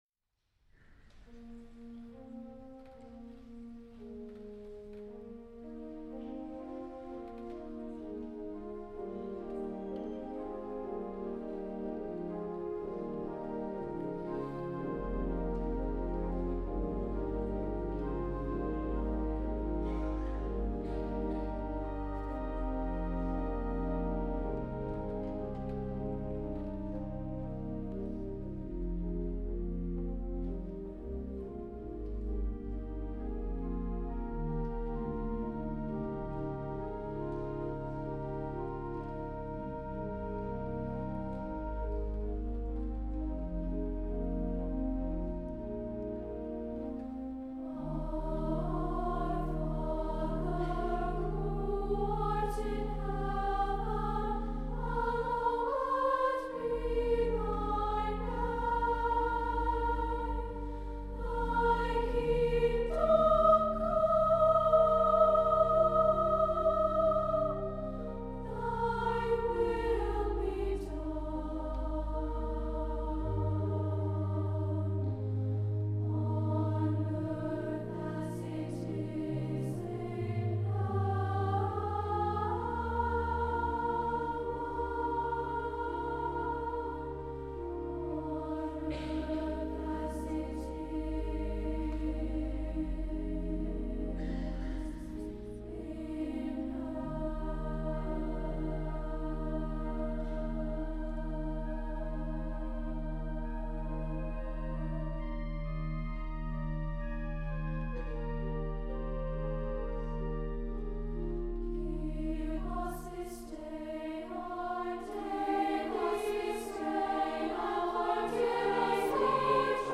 for SSA Chorus and Organ (1988)
This return is the climax of the work, with the choir singing in a 3-part canon.
This is an extended (4-minute) and dramatic setting of The Lord's Prayer.